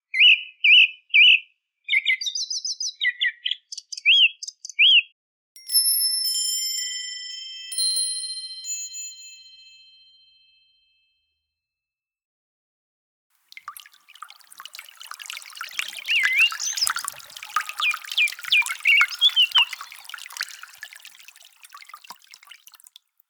Zwitscherbox Lily Bells Selection, set van drie vogelgeluiden
Samen zorgen ze voor een natuurlijk en speels samenspel van vogelgeluiden, aangevuld met zachte belletjes en subtiele waterklanken.
Geluid merel, zanglijster en nachtegaal met zachte klankaccenten